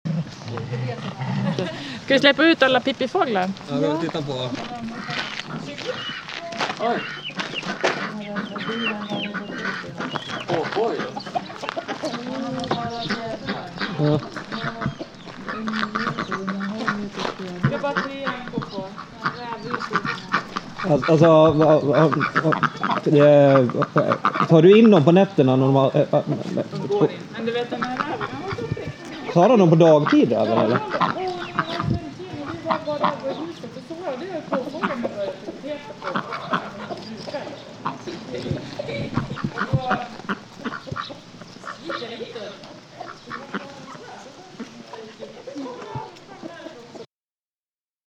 När mjölkningen är klar släpper vi ut hönsen och påfåglarna.